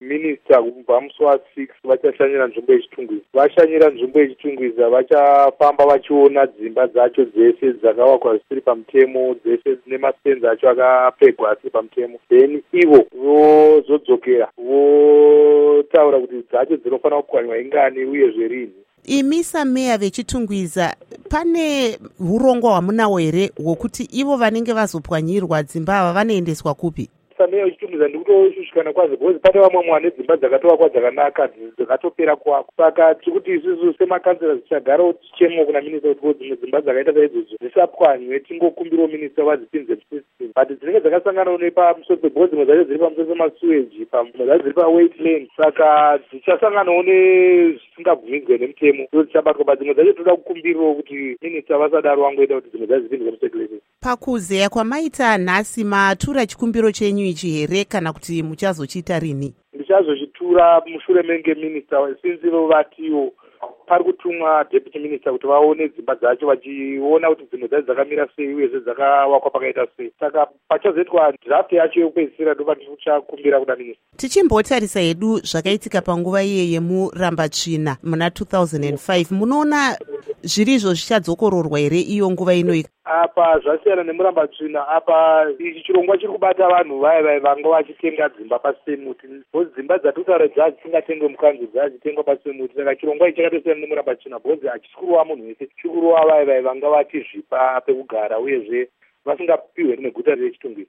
Embed share Hurukuro NaMayor Phillip Mutoti by VOA Embed share The code has been copied to your clipboard.